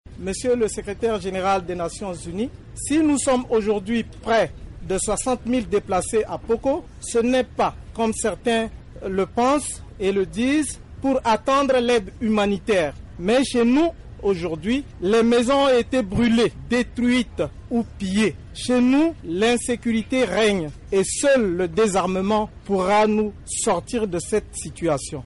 un des déplacés de M'Poko